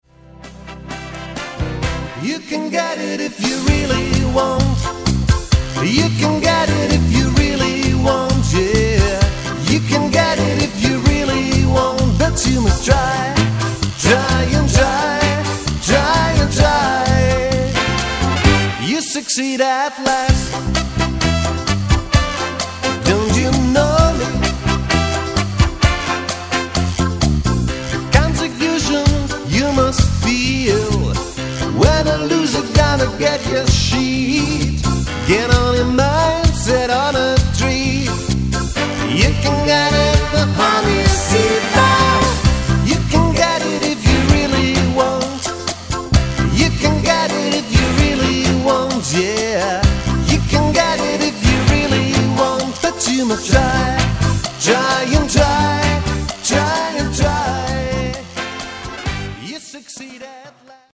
Vocals, Bass, Keys, Trumpet
Guitar, Trombone
Drums